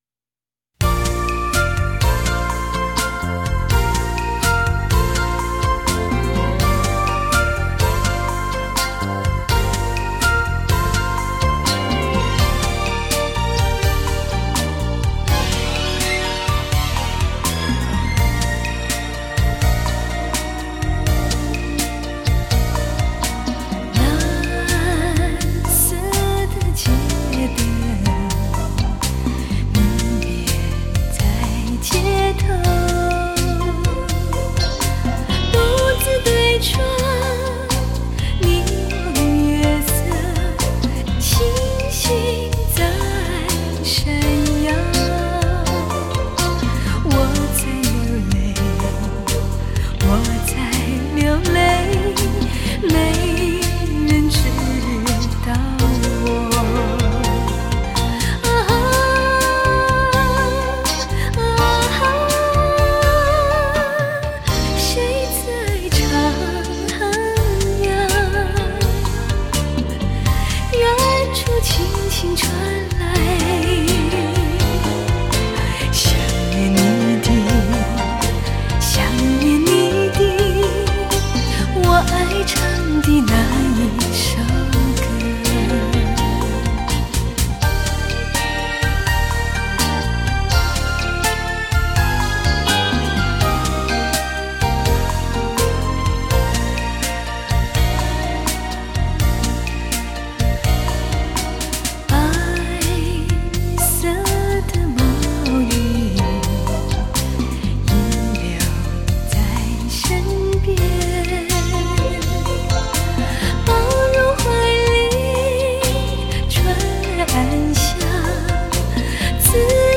华视连续剧主题曲